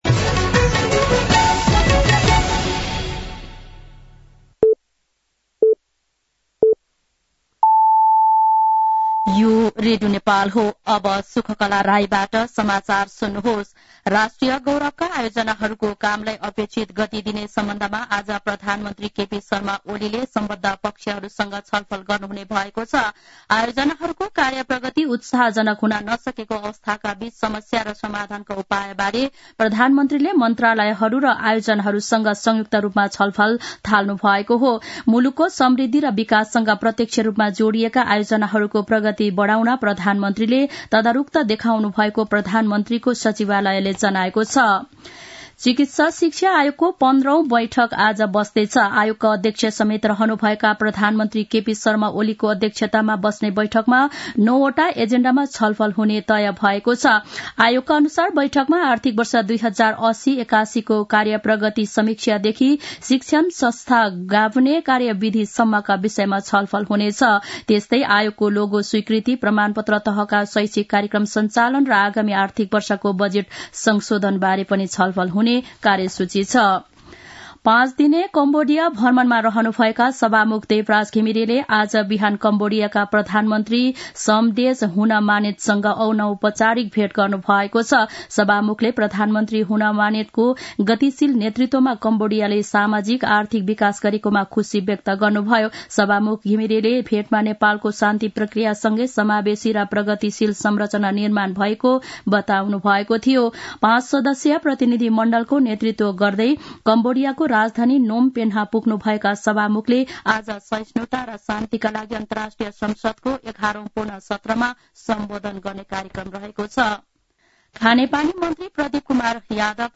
दिउँसो १ बजेको नेपाली समाचार : १० मंसिर , २०८१
1-am-Nepali-News.mp3